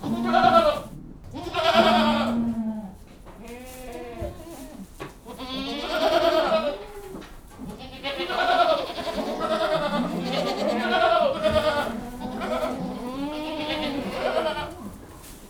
GOATS.wav